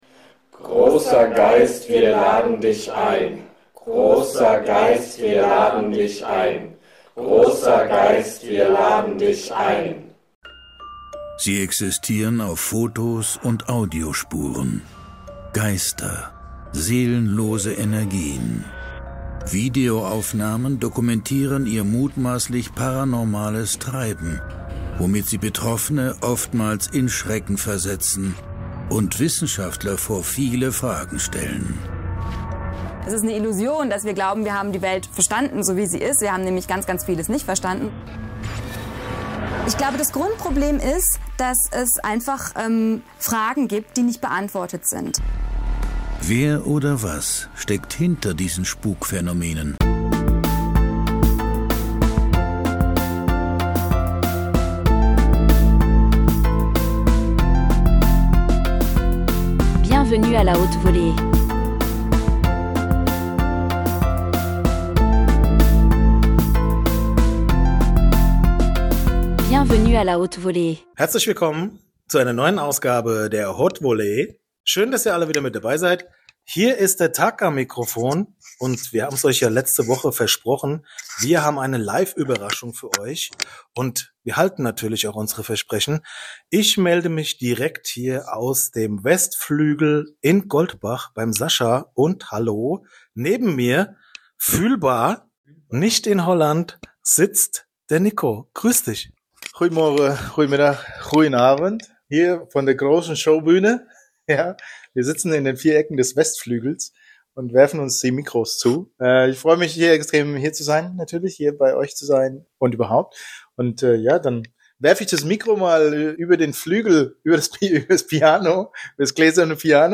Ohne Skript, ohne Nische, ohne Absicherung!